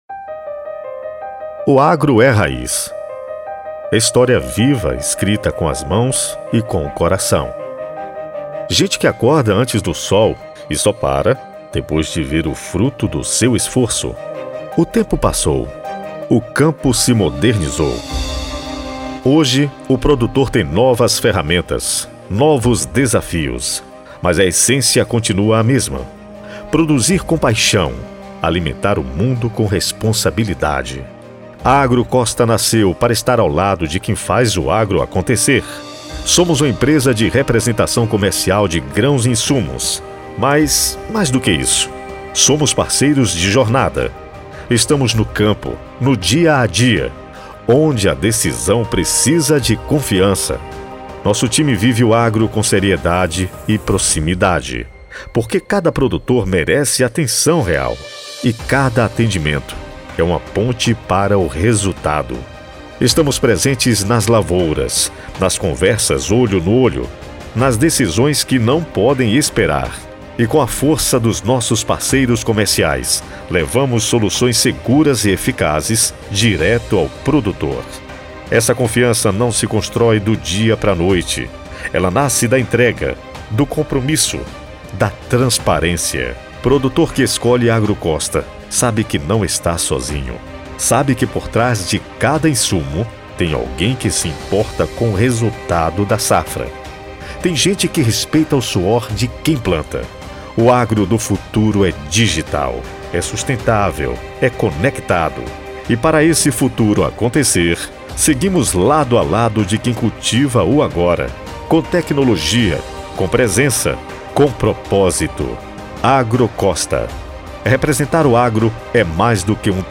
Spot Comercial